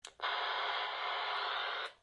talky-walky-on.mp3